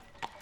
马蹄1.wav